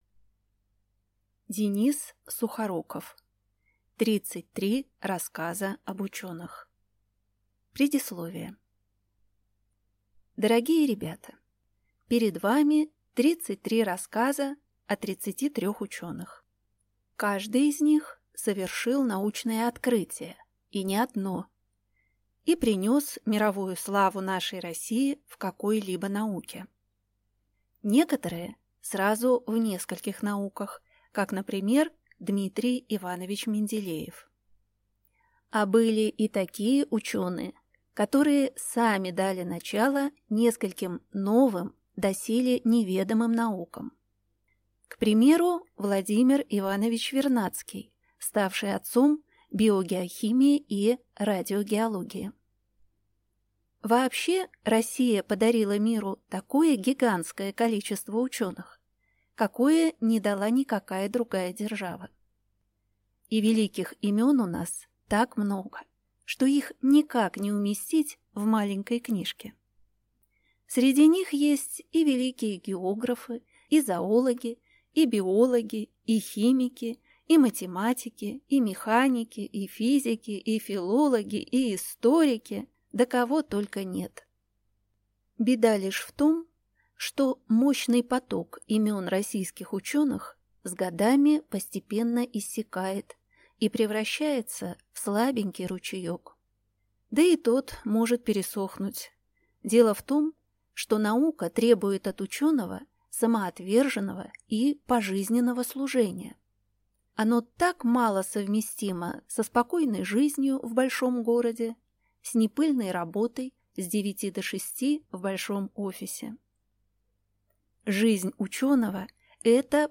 Аудиокнига Тридцать три рассказа об ученых | Библиотека аудиокниг